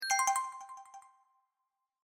correcto.mp3